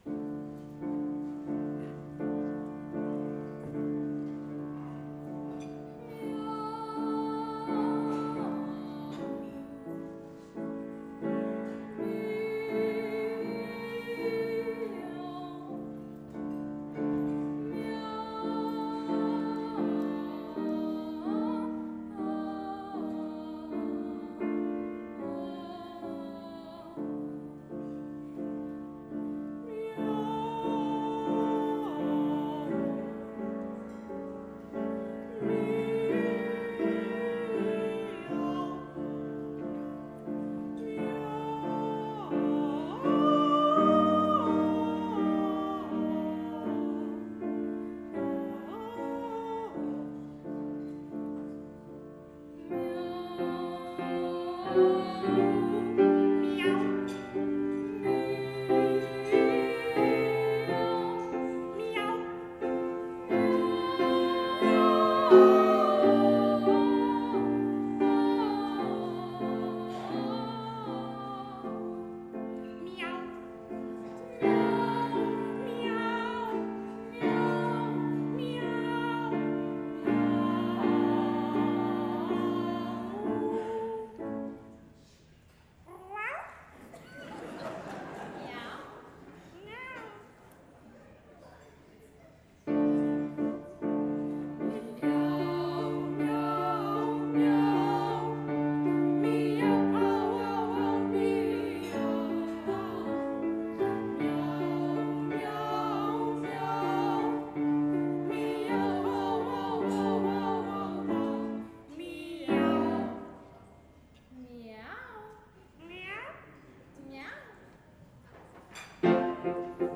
Alfdorfer Jugendchor – Duetto Buffo di due Gatti (21.01.2017 Jahresfeier)